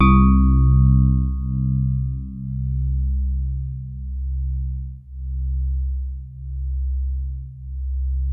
FINE HARD C1.wav